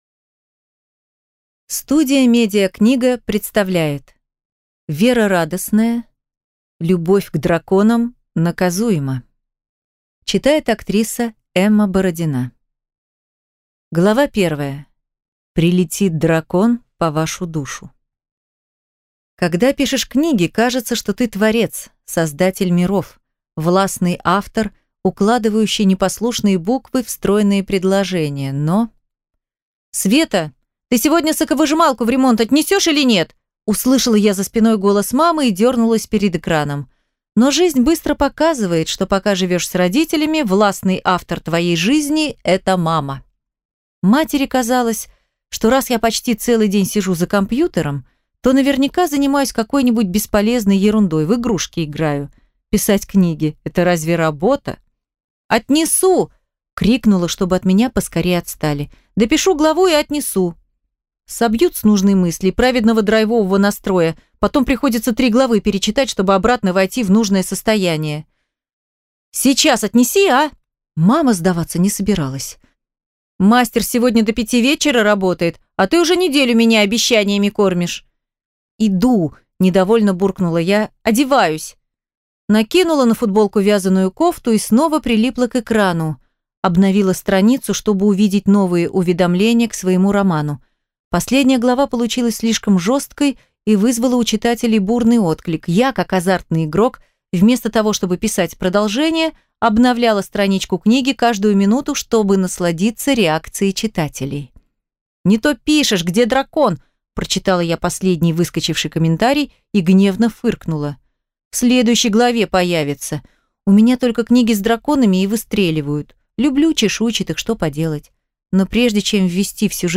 Аудиокнига Любовь к драконам наказуема | Библиотека аудиокниг
Прослушать и бесплатно скачать фрагмент аудиокниги